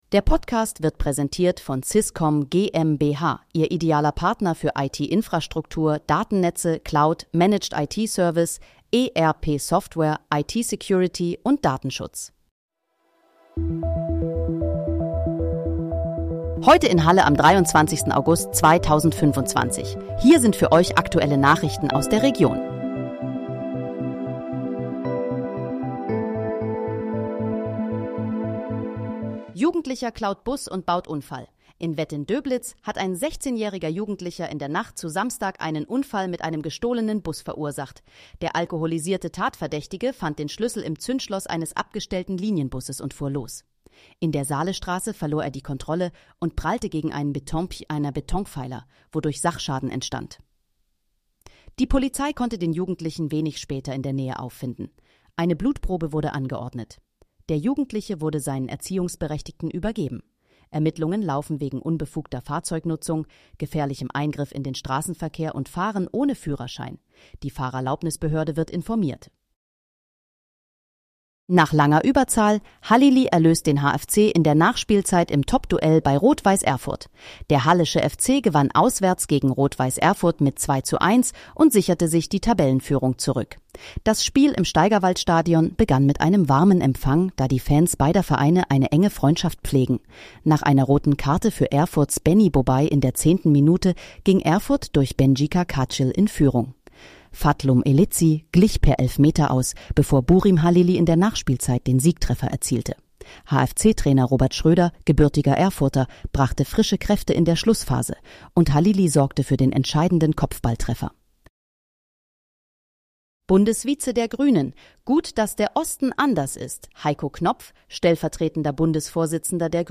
Heute in, Halle: Aktuelle Nachrichten vom 23.08.2025, erstellt mit KI-Unterstützung
Nachrichten